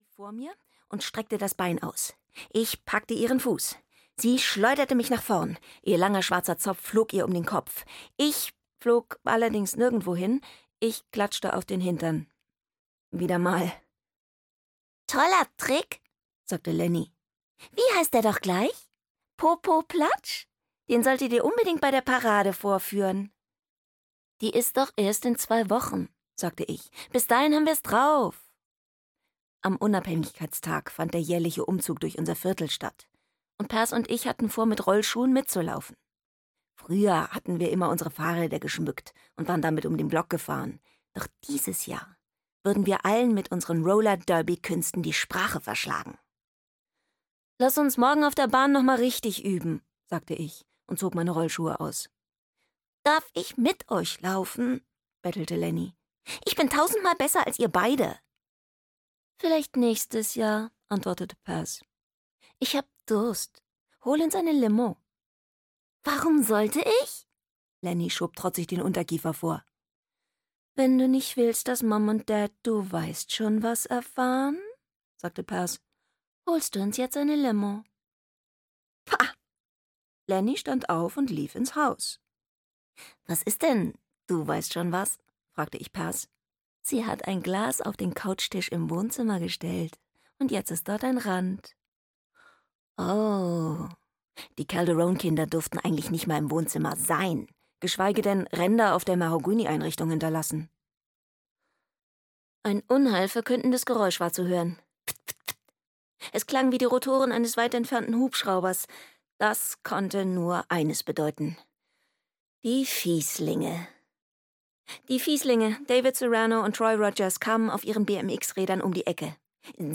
Ein Baum voller Geheimnisse - Natalie Standiford - Hörbuch